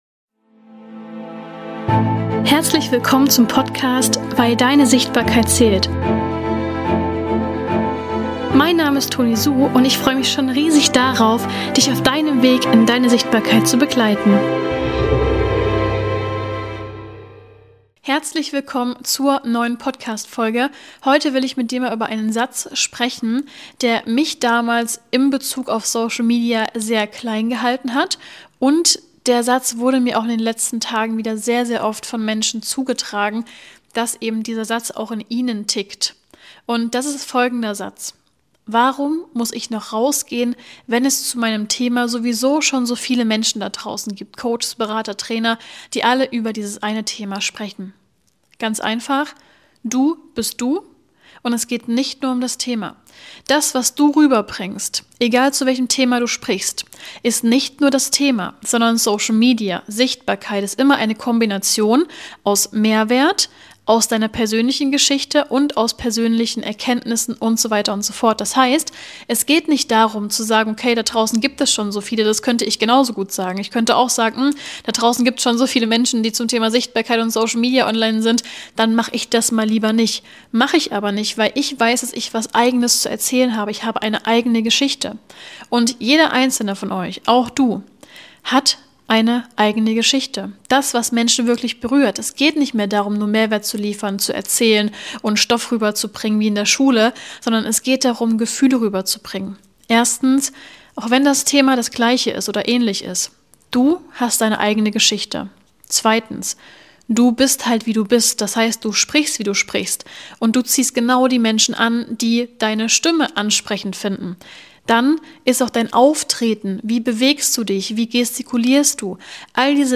Teile Deine Geschichte - im Gespräch